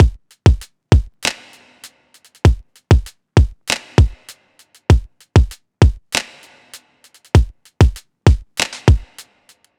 Index of /99Sounds Music Loops/Drum Loops/Hip-Hop